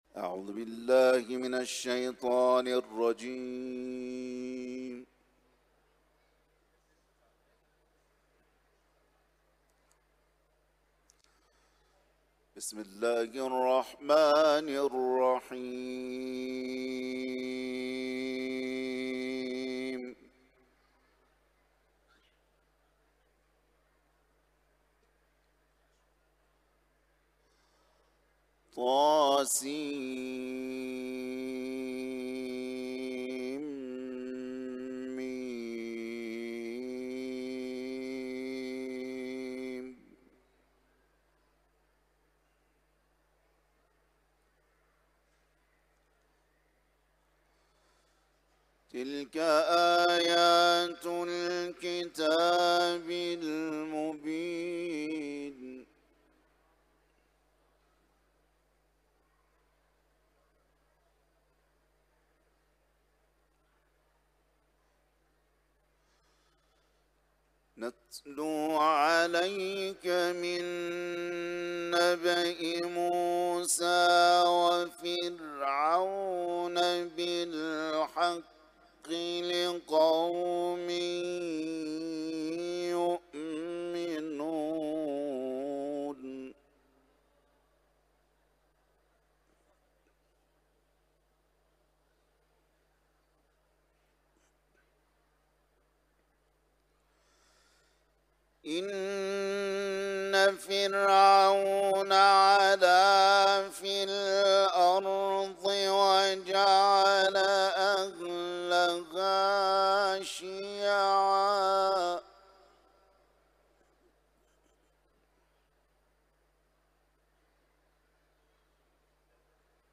در حرم مطهر رضوی تقدیم مخاطبان ایکنا می‌شود.
سوره قصص ، تلاوت قرآن